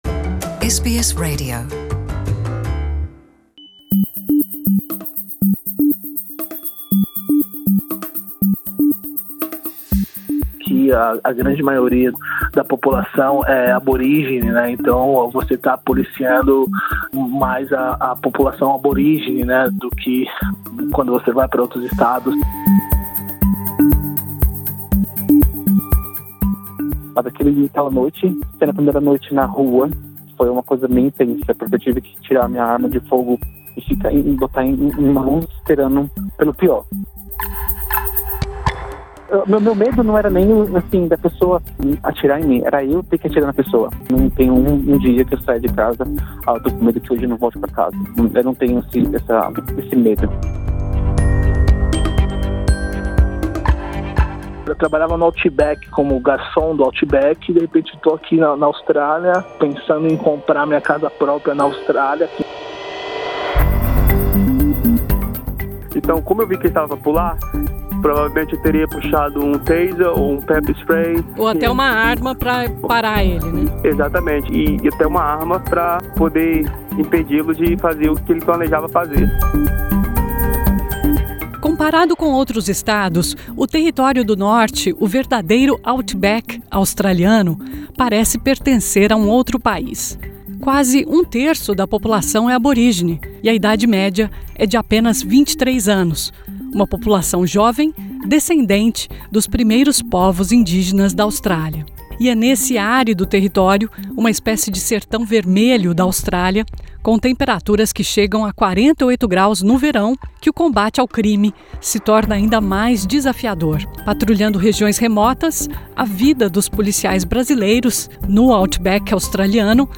Conversamos com três policiais brasileiros que escolheram viver no verdadeiro outback australiano.